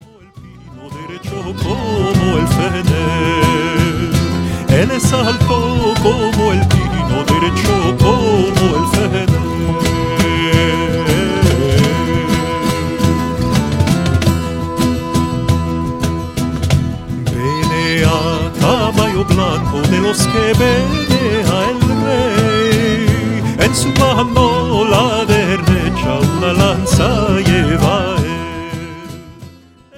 Turkish folk song